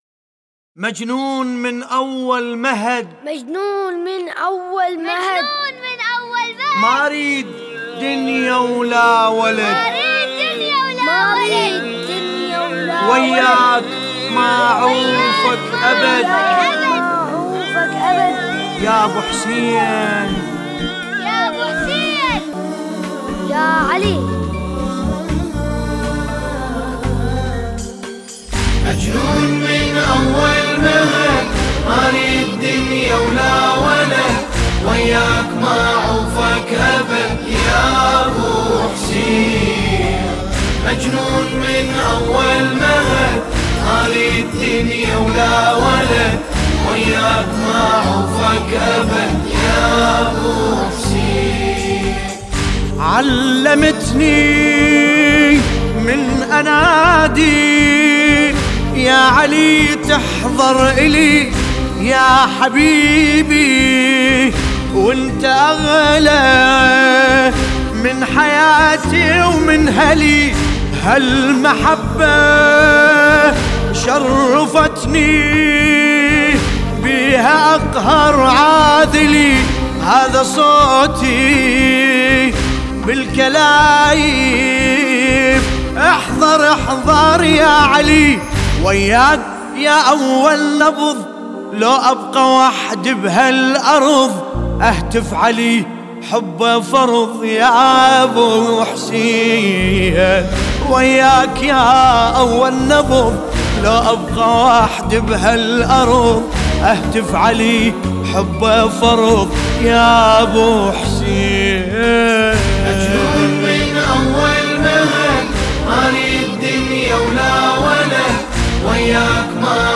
سينه زنی